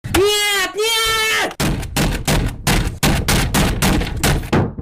net neeeeet titan Meme Sound Effect
This sound is perfect for adding humor, surprise, or dramatic timing to your content.